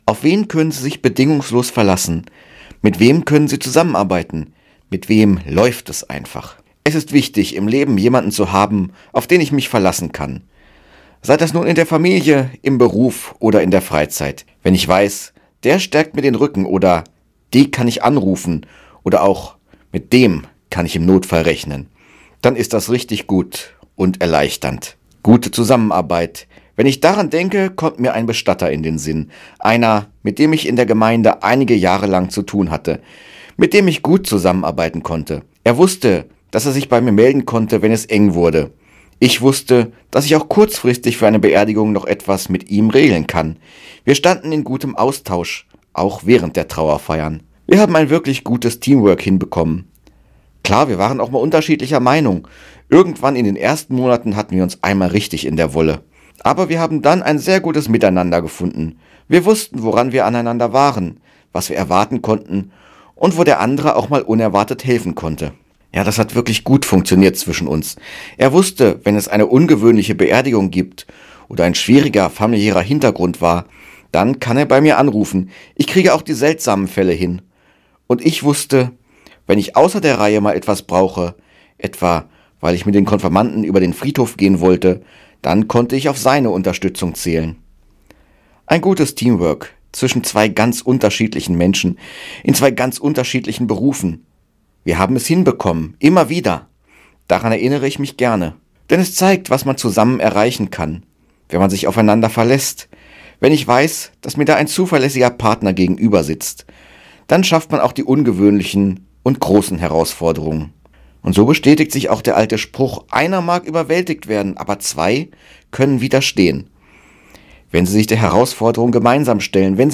Radioandacht vom 18. Dezember